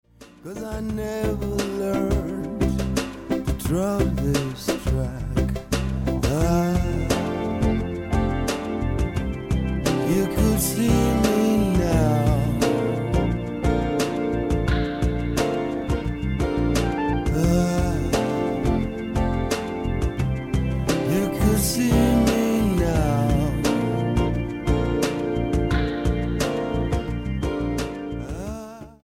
Reggae EP